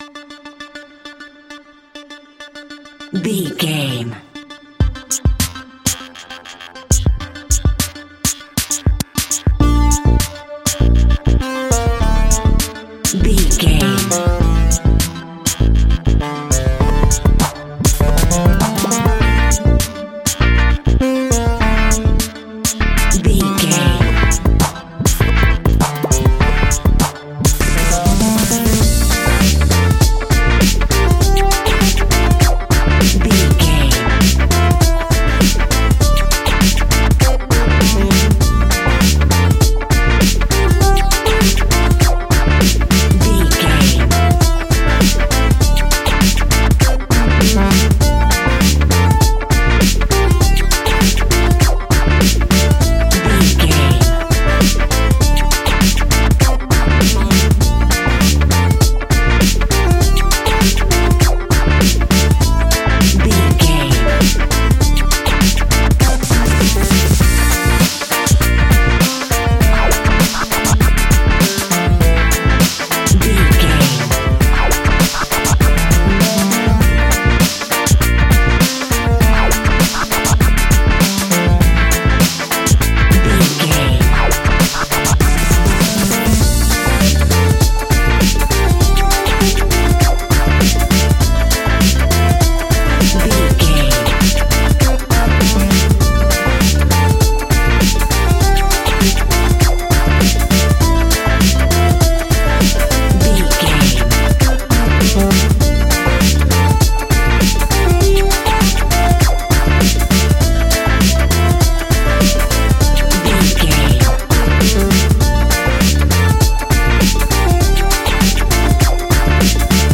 Aeolian/Minor
D
hip hop
hip hop instrumentals
downtempo
synth lead
synth bass
synth drums